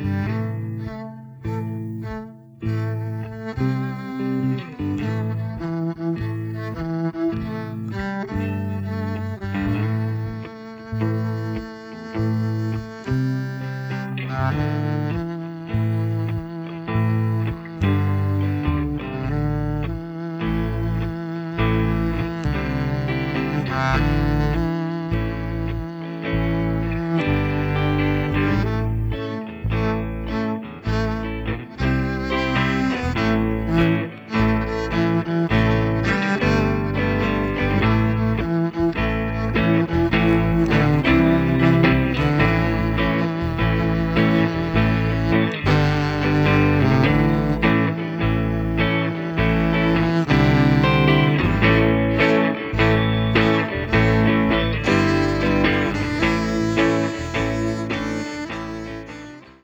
(rhythmic viola groove)